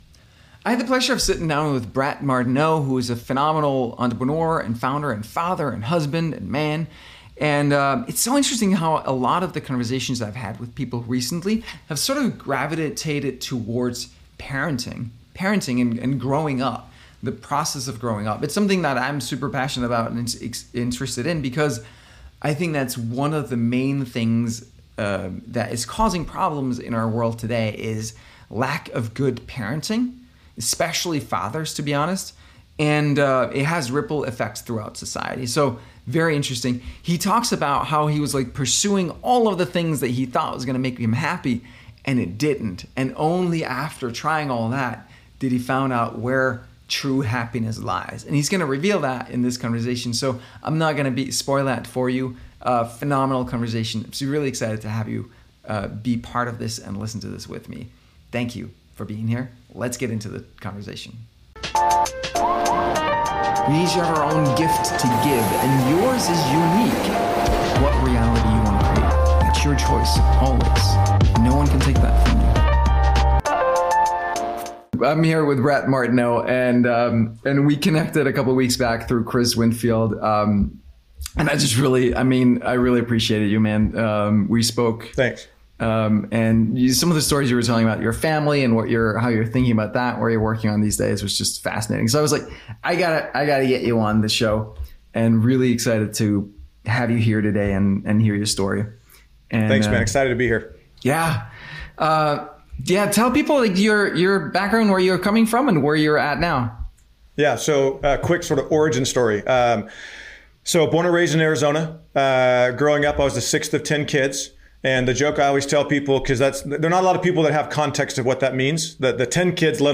Parenting and Purpose Interview